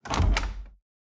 Chest Close 1.ogg